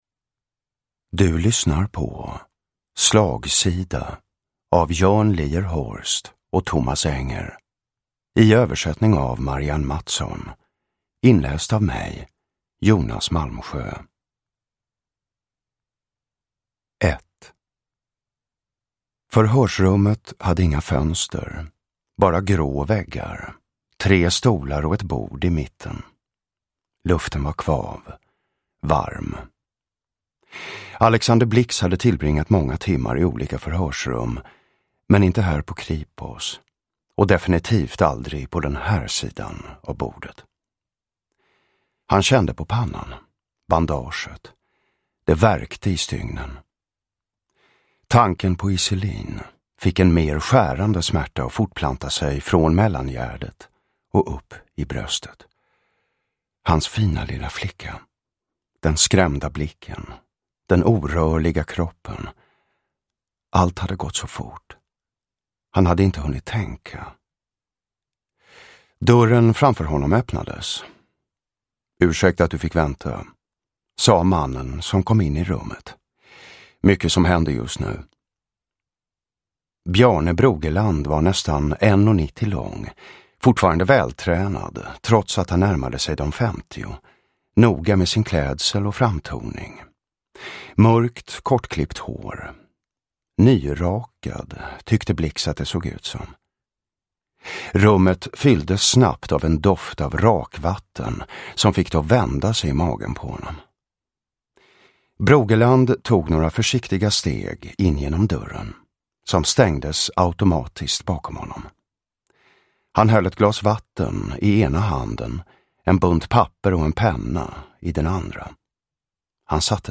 Uppläsare: Jonas Malmsjö